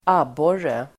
Ladda ner uttalet
Uttal: [²'ab:år:e]